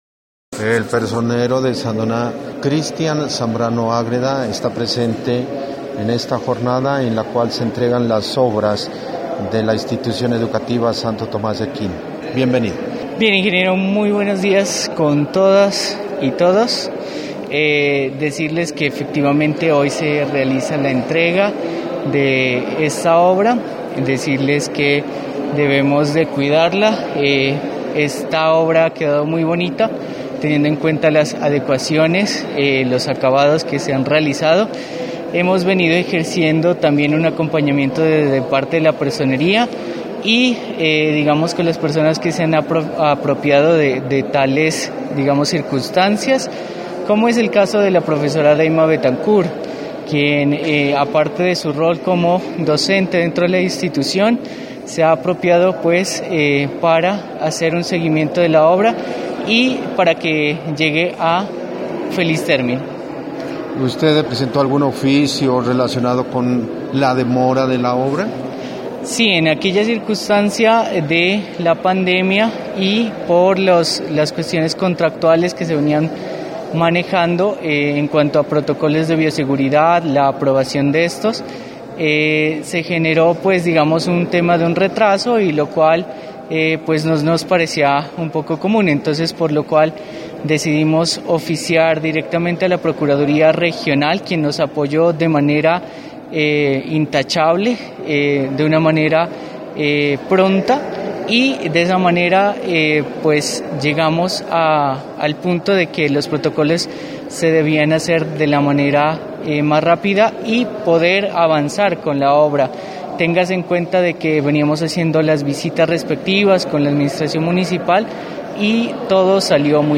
Los ingenieros responsables del proyecto de adecuación del salón de actos y construcción de la cubierta del polideportivo de la Institución educativa Tomás de Aquino de Sandoná hicieron entrega de las obras este viernes en horas de la mañana.
Personero Christian Zambrano Ágreda: